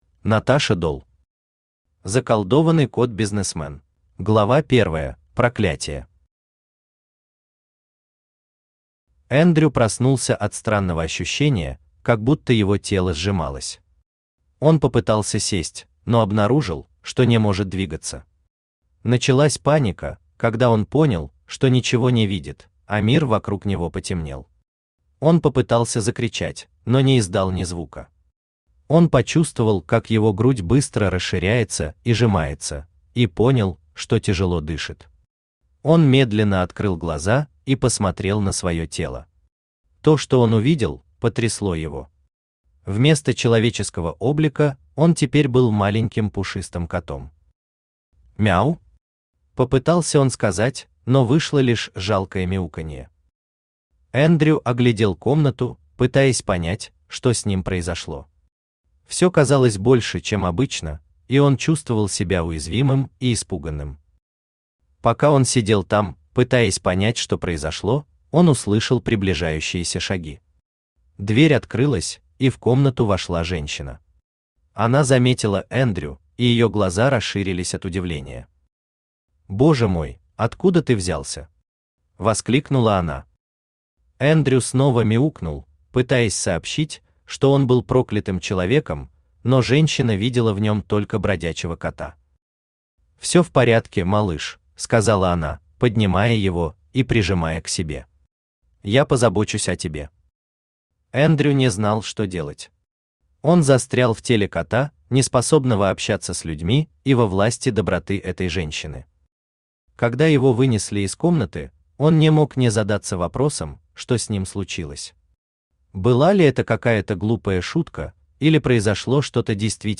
Аудиокнига Заколдованный кот-бизнесмен | Библиотека аудиокниг
Aудиокнига Заколдованный кот-бизнесмен Автор Наташа Дол Читает аудиокнигу Авточтец ЛитРес.